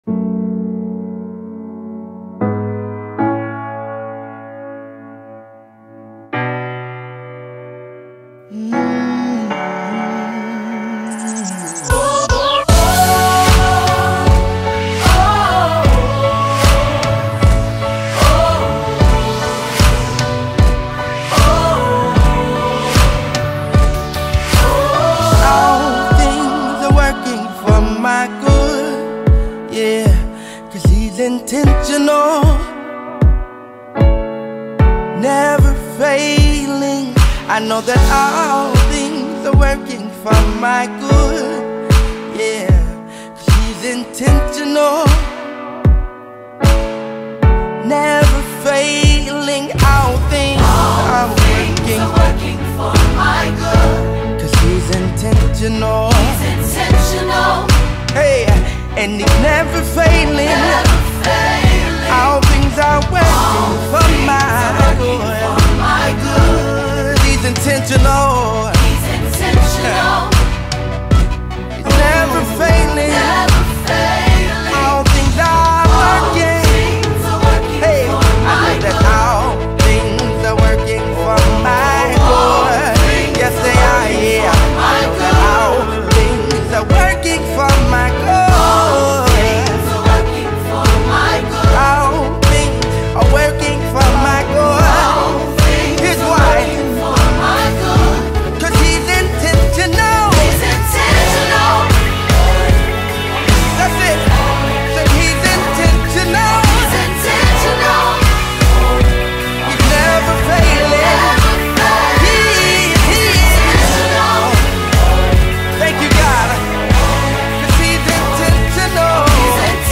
Genre:Gospel